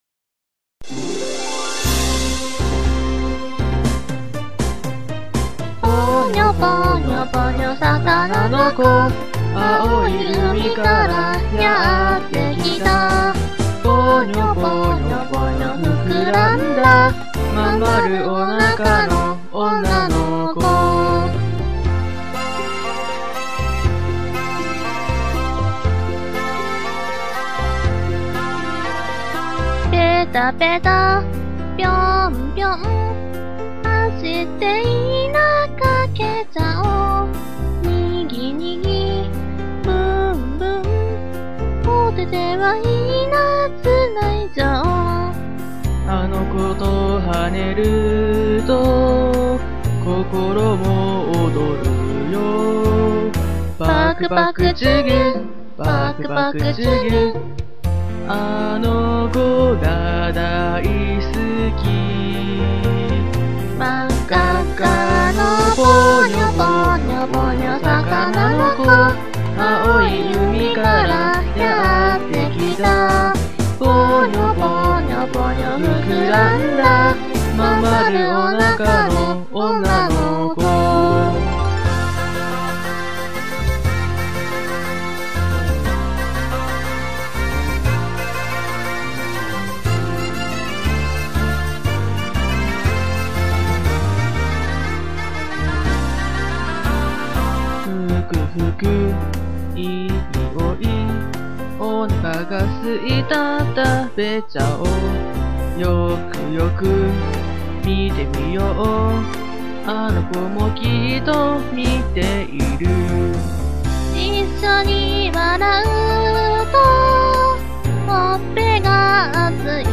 ジャンル：歌ってみた
歌ってみたです。
音楽を余り聴かずに歌ったので、時々間違ってたりします...orz